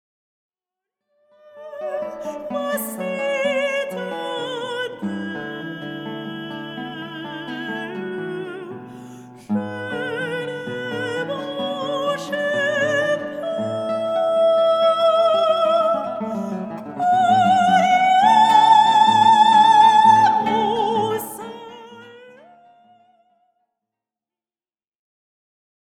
Chants sacrés
La rencontre de la guitare et de la voix.
accompagnées à la guitare